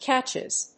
/ˈkætʃɪz(米国英語)/